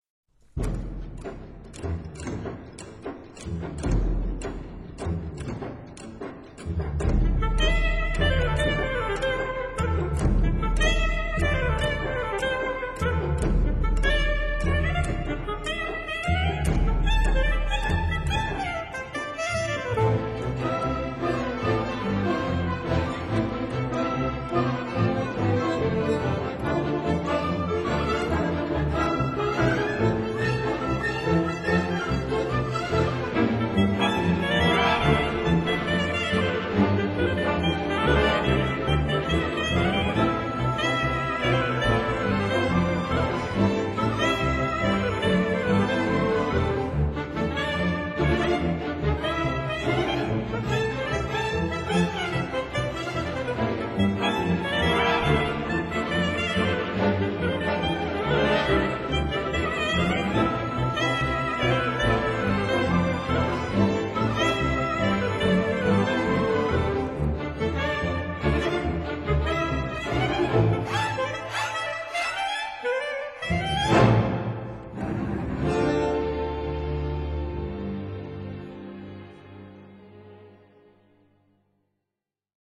scritto nello stile del tango argentino
For Saxophone and Symphony Orchestra
(Alto sax and orchestra)